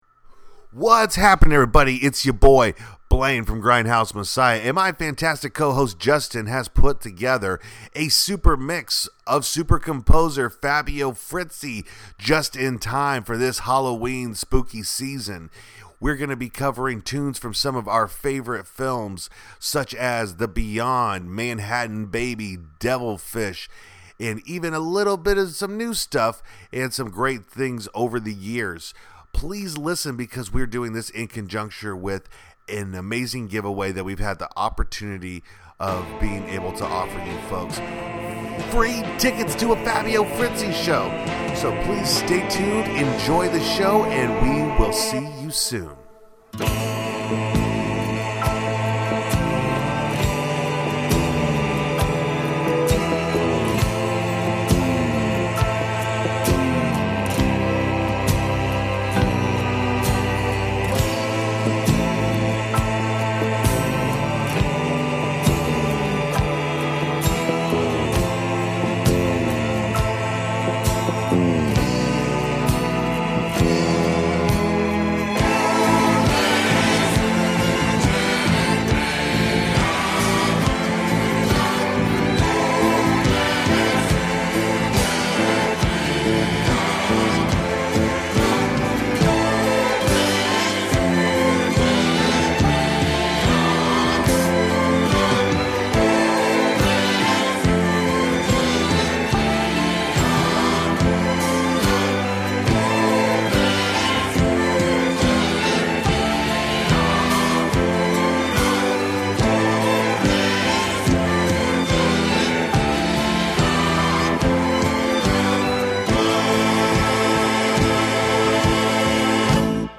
a 30 minute mix